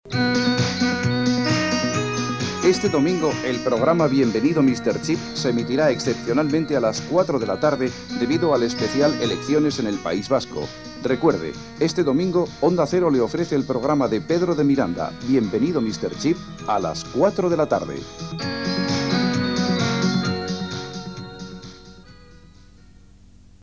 Promoció del programa d'informàtica i tecnologia avisant d'un canvi puntual en l'hora d'emissió.